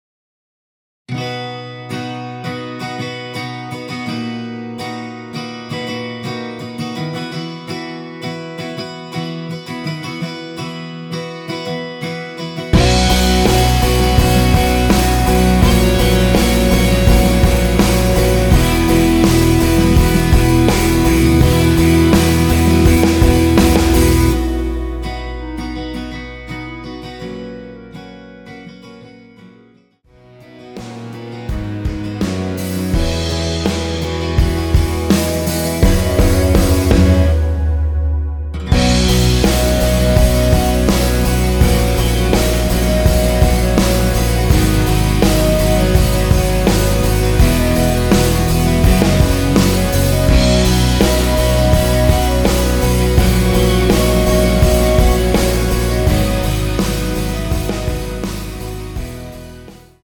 원키에서(-1)내린 (1절앞+후렴)으로 진행되게 편곡된 멜로디 포함된 MR입니다.(미리듣기 확인)
앞부분30초, 뒷부분30초씩 편집해서 올려 드리고 있습니다.
중간에 음이 끈어지고 다시 나오는 이유는